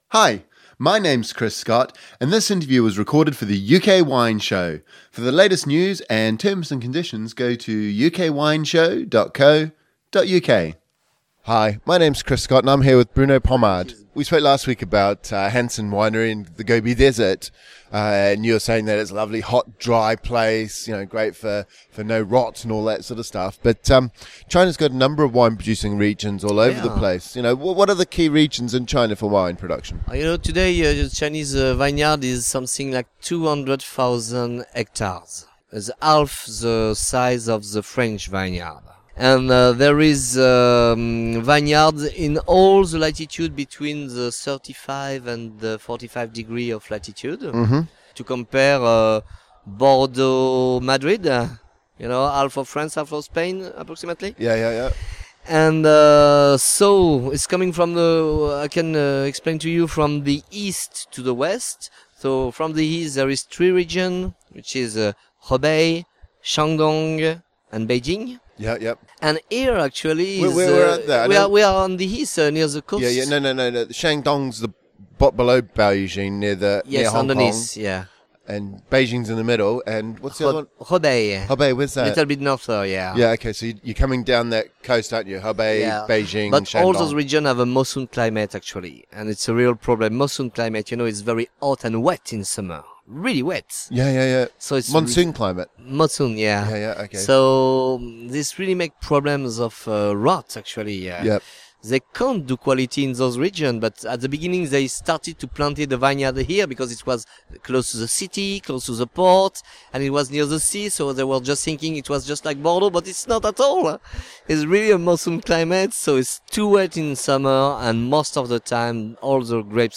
» Listen to the full UK Wine Show